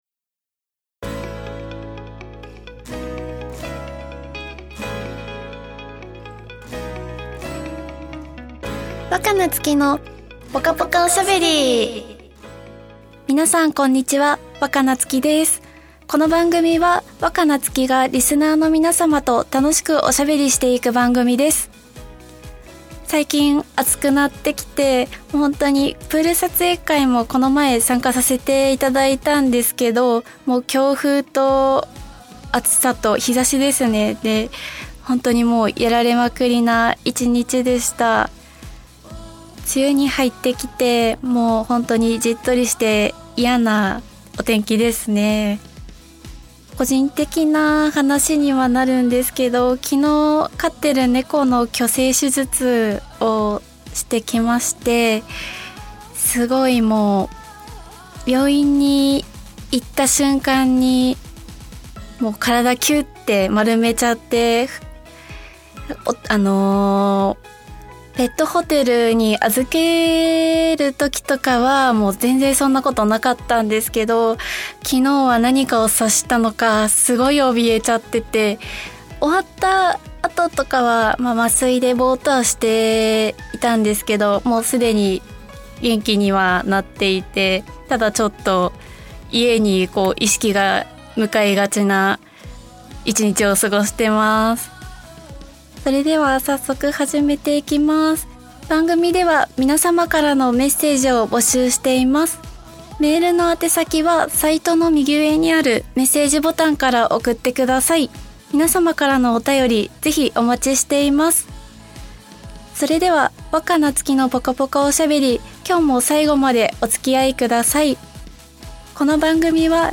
初回配信沢山の方に聞いていただきありがとうございます♪今月は前回より緊張もほぐれ滑らかにトークできました！？